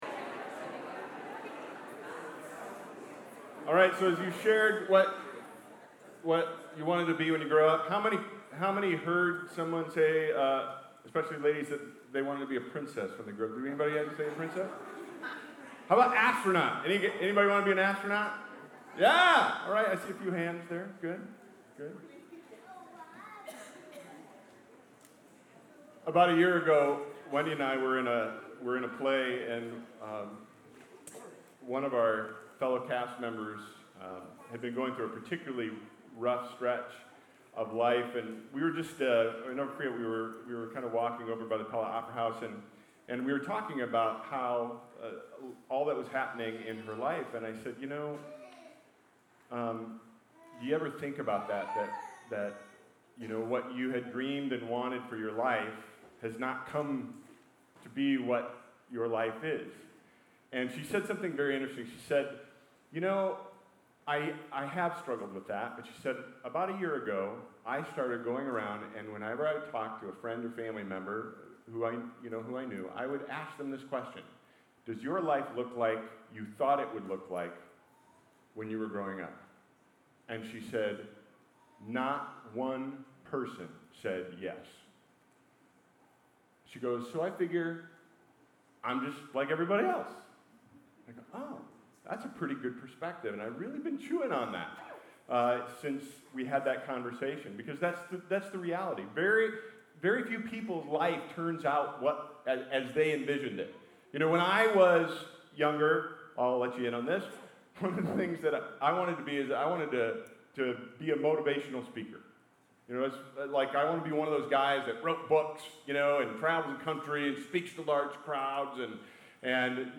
The message series was part of the Auditorium services at Third Church in Pella, Iowa.